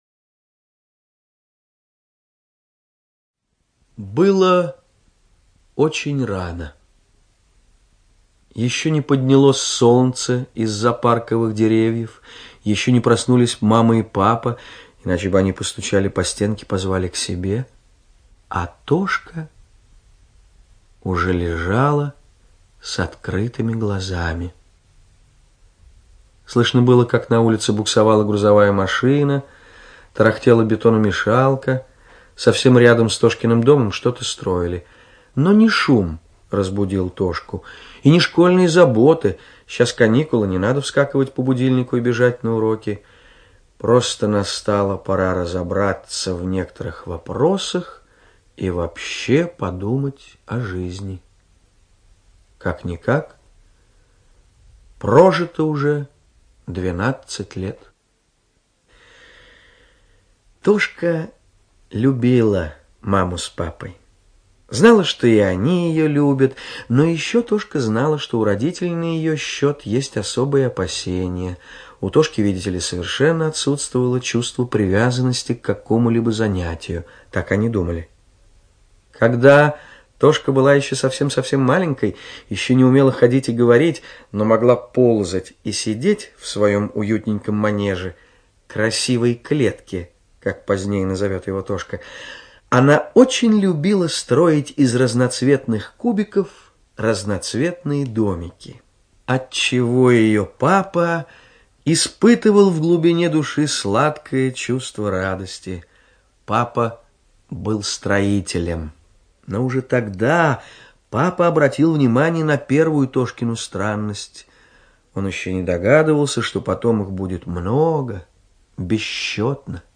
ЧитаетТабаков О.